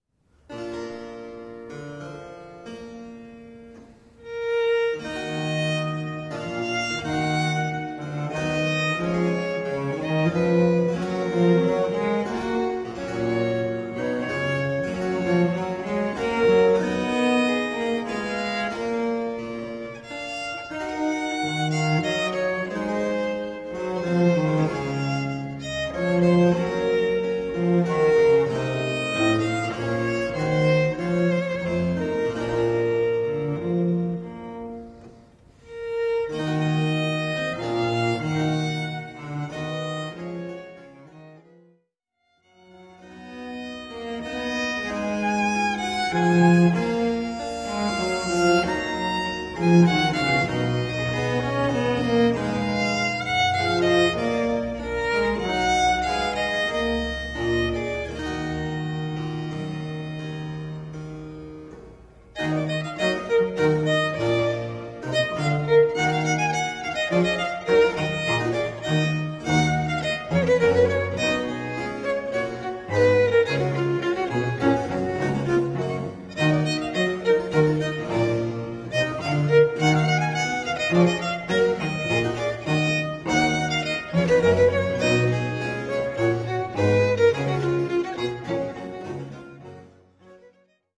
A CD of music for extant Baroque dance choreographies.
Harpsichord
Baroque Cello
Baroque Flute, Treble and Descant Recorders
Viola da Gamba
Baroque Violin